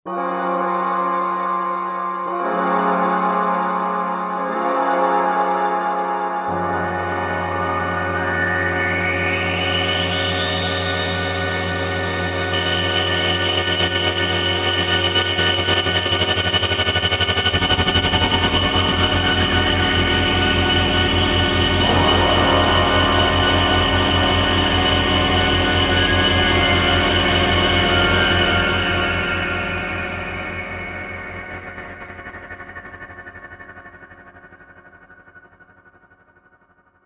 lab Roland VSynth
Scarypad.mp3